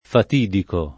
fatidico